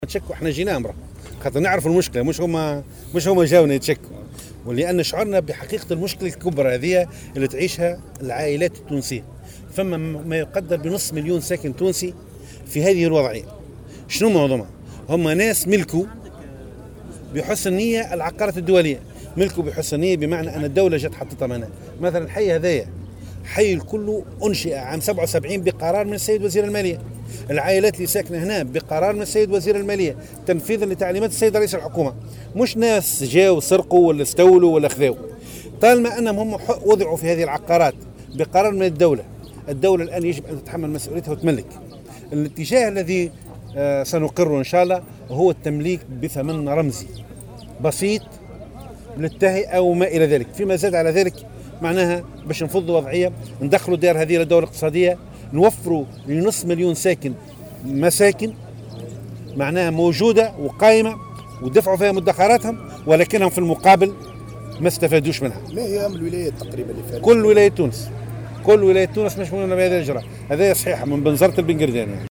وأوضح لمراسل "الجوهرة اف أم"